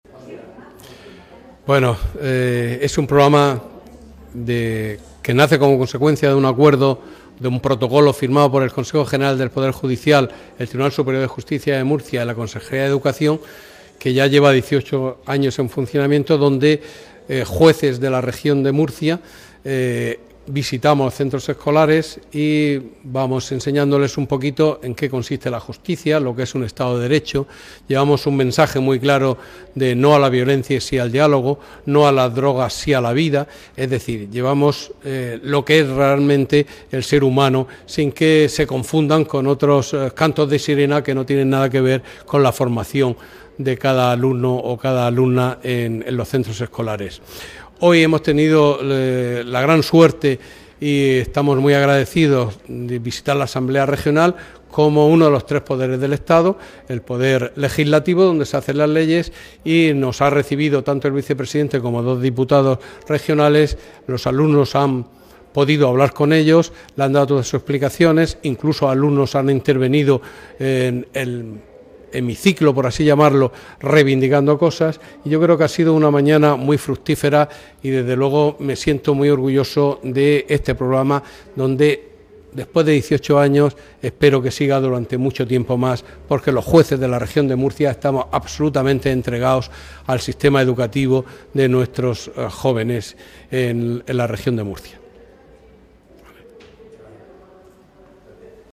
• Declaraciones del Magistrado de la Sala Civil y Penal del Tribunal Superior de Justicia de la Región de Murcia, Joaquin Ángel de Domingo